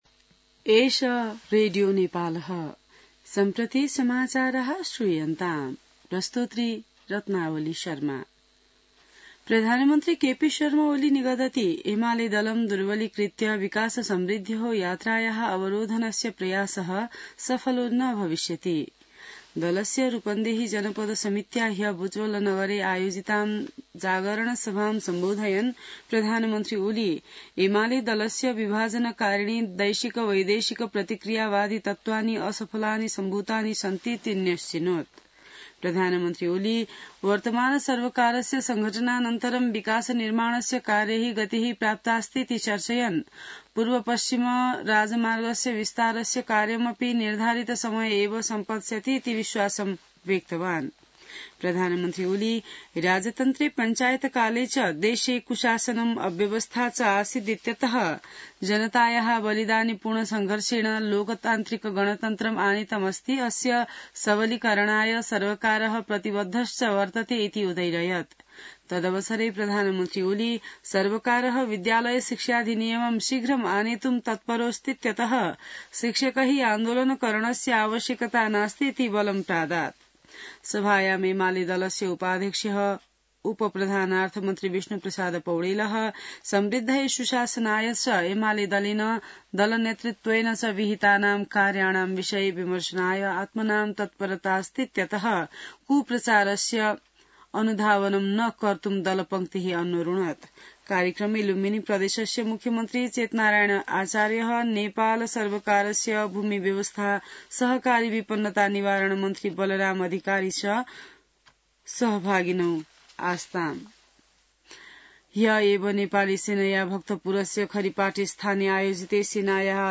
संस्कृत समाचार : ३० चैत , २०८१